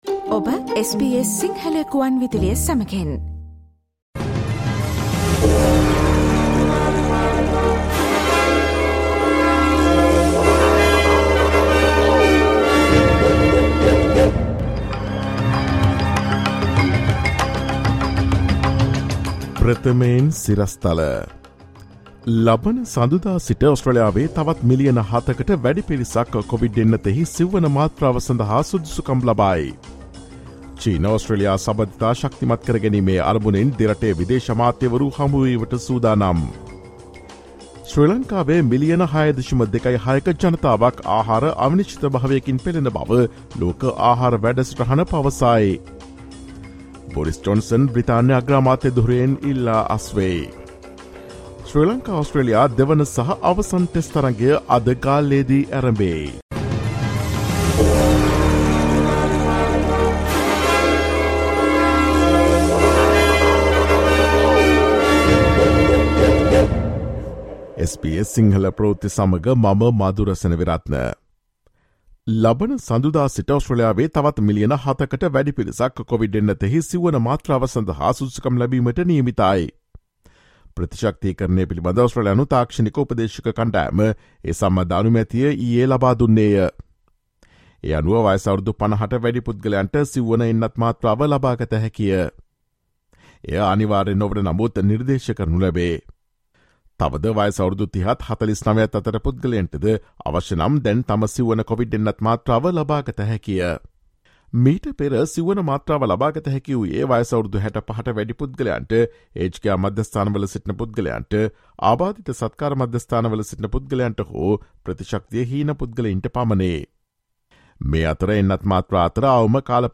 ඔස්ට්‍රේලියාවේ සහ ශ්‍රී ලංකාවේ නවතම පුවත් මෙන්ම විදෙස් පුවත් සහ ක්‍රීඩා පුවත් රැගත් SBS සිංහල සේවයේ 2022 ජූලි 08 වන දා සිකුරාදා වැඩසටහනේ ප්‍රවෘත්ති ප්‍රකාශයට සවන් දීමට ඉහත ඡායාරූපය මත ඇති speaker සලකුණ මත click කරන්න.